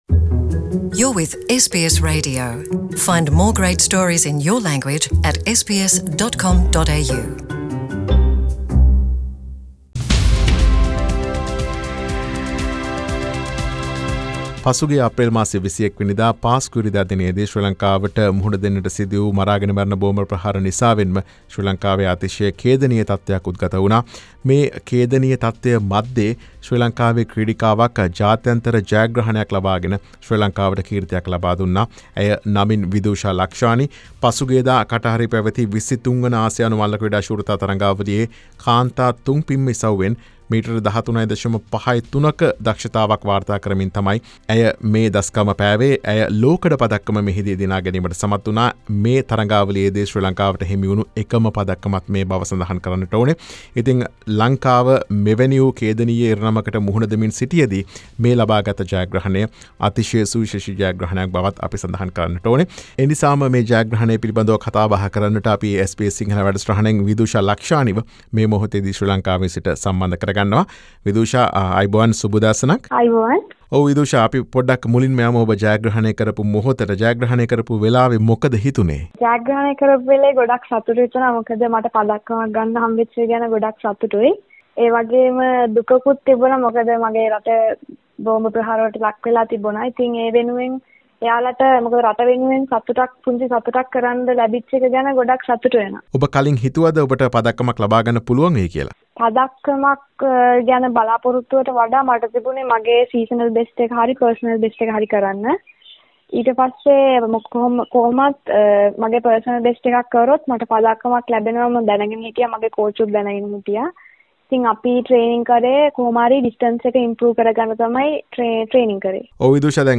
පාස්කු ඉරිදා ප්‍රහාරයේ කම්පනය මැද ලක්මවට පදක්කමක් ගෙනදුන් දිරිය දියණිය - විදුෂා ලක්ෂානි SBS සිංහලට කතා කරයි.